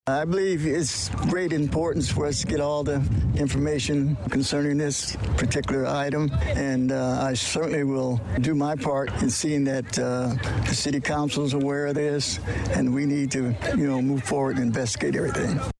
A similar sentiment was expressed by a member of the Danville City Council that was there; Alderman Robert Williams.